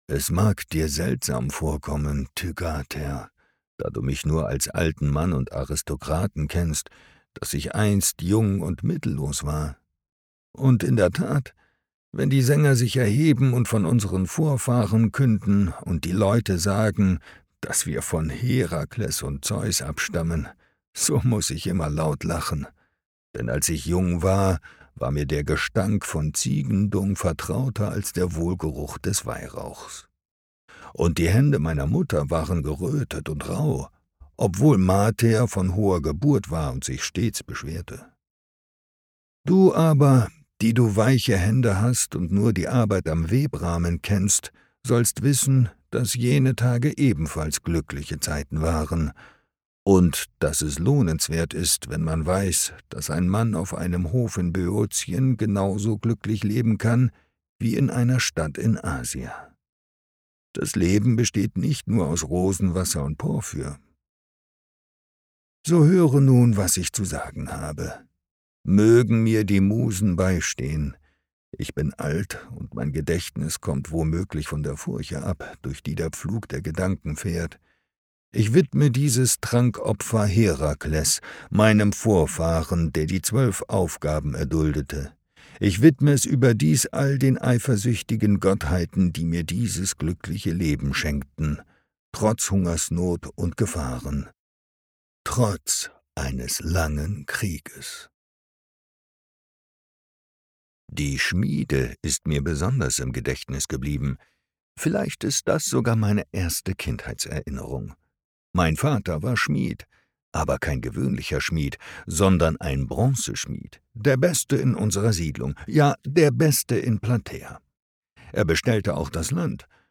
2019 | 1. Leicht gekürzte Lesung